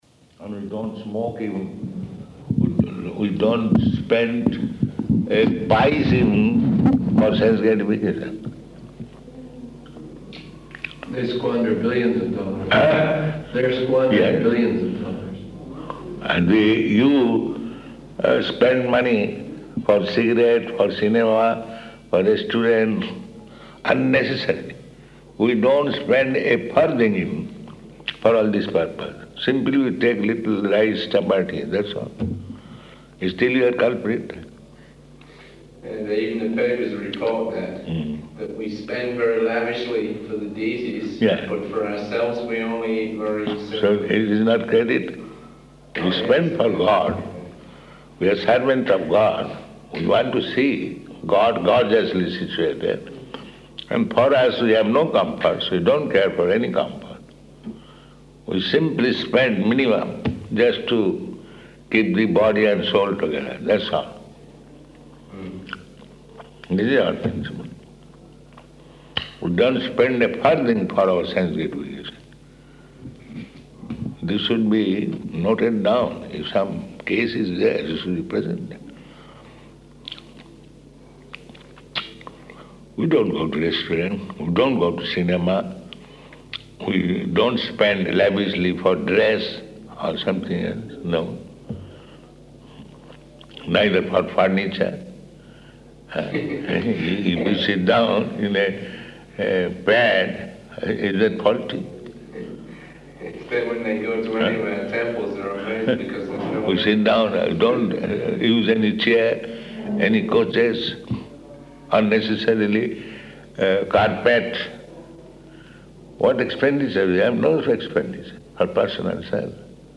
Room Conversation
Type: Conversation
Location: Vṛndāvana